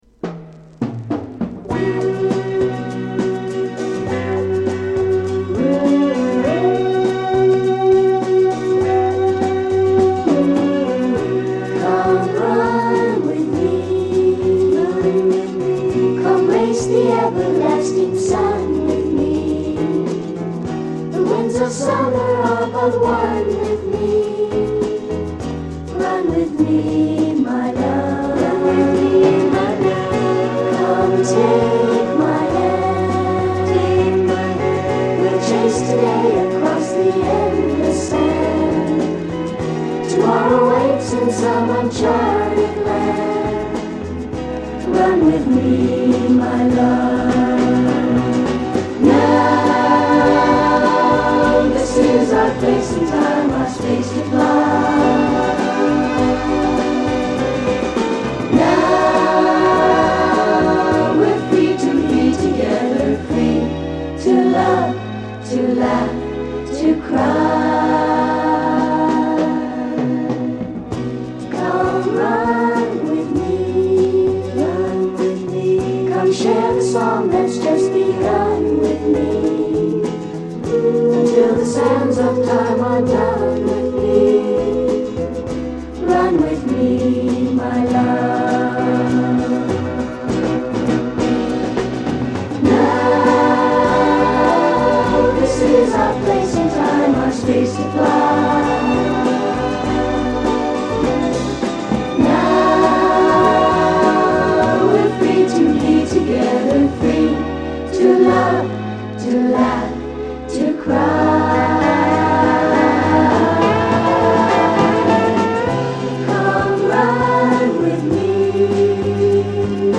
KIDS SOFT ROCKコーラスの大傑作！大人気キッズソウル